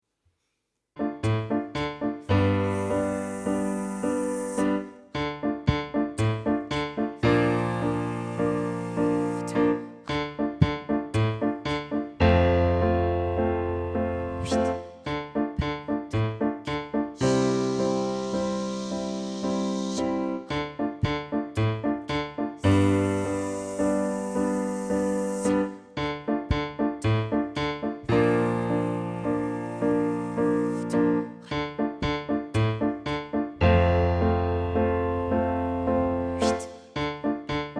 exemple d'exercice vocal
Exercice-n°1-tonicité-abdominale.mp3